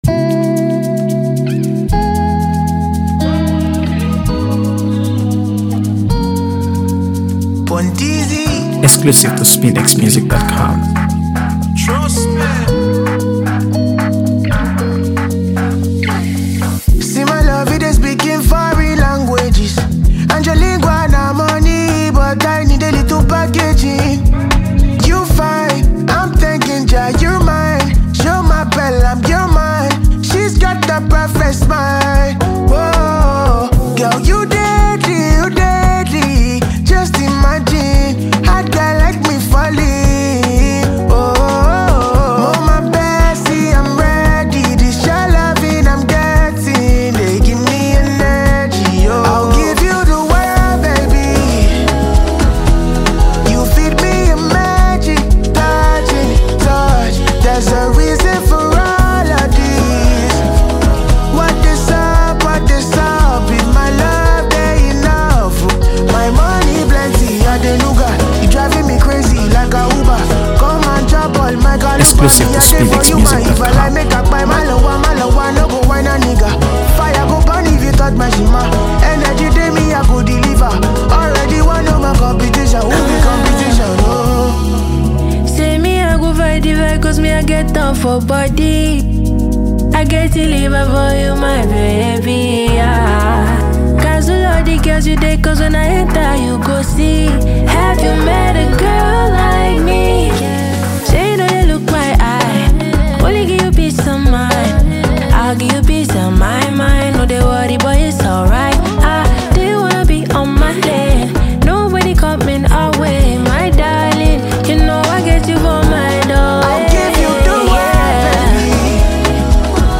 AfroBeats | AfroBeats songs
smooth vocals and melodic precision
a seamless mix of vibrant Afropop and heartfelt expression